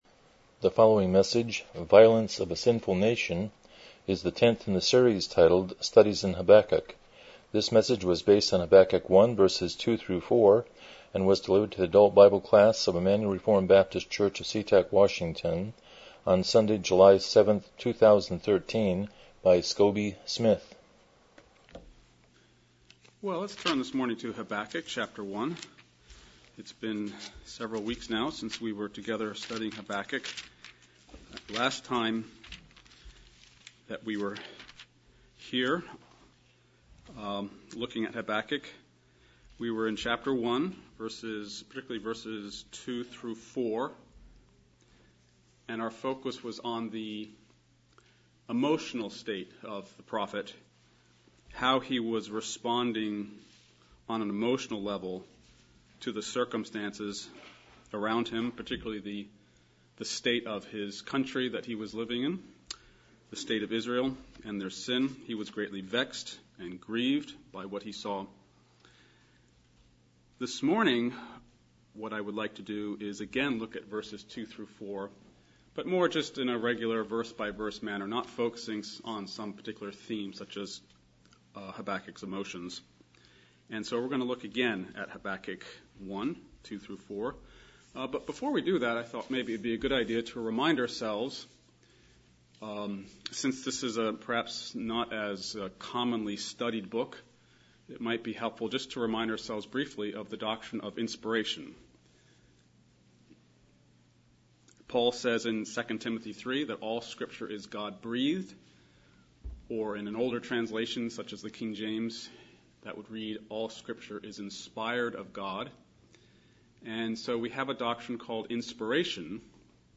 Series: Studies in Habakkuk Passage: Habakkuk 1:2-4 Service Type: Sunday School